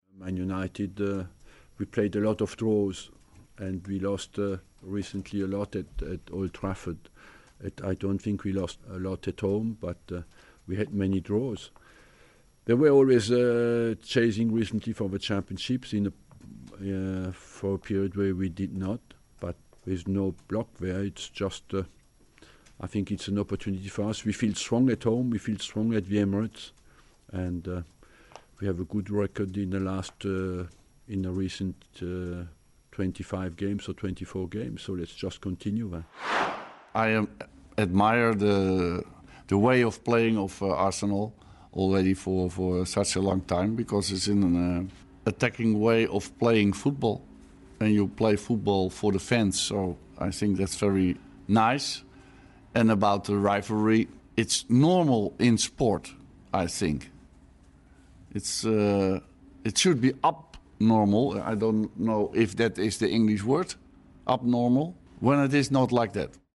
Arsene Wenger and Louis van Gaal have been speaking about the rivalry between Arsenal and Manchester United ahead of the Saturday Night Football.